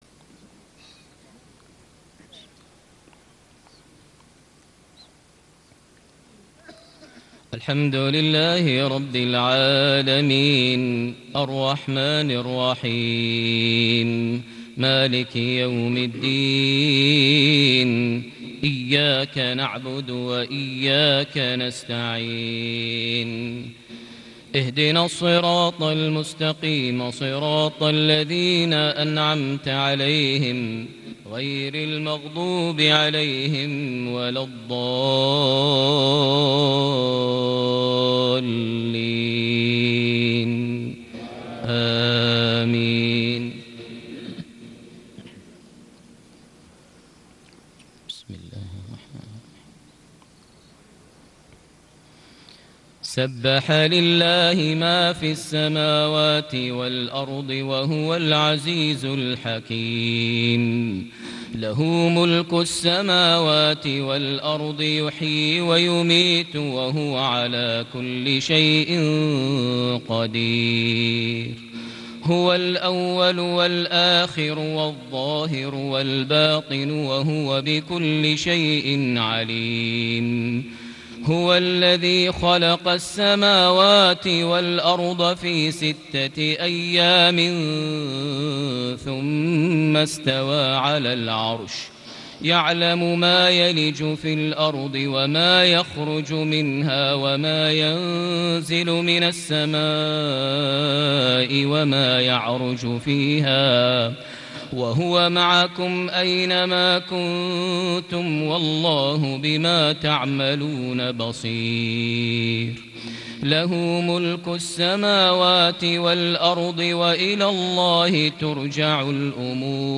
صلاة الفجر ٢٣ ذو القعدة ١٤٣٨هـ سورة الحديد ١-١٦ > 1438 هـ > الفروض - تلاوات ماهر المعيقلي